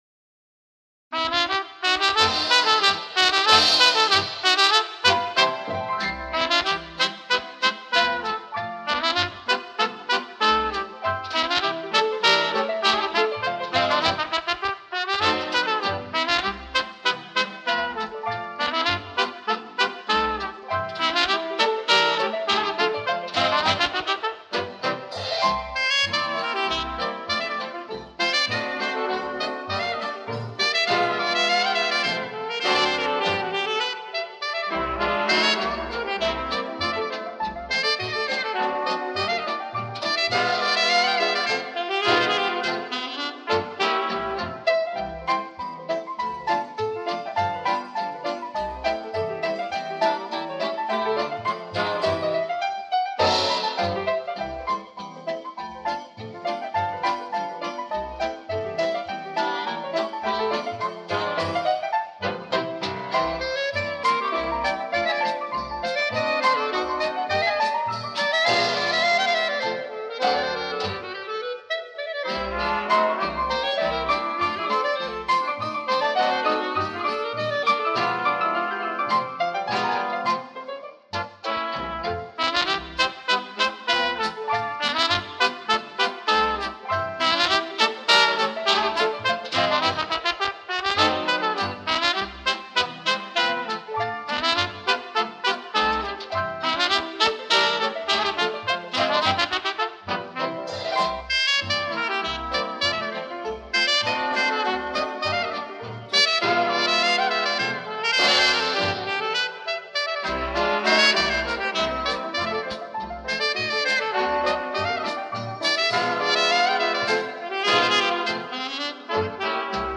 Звук нормализован но пикам, а не по средней громкости.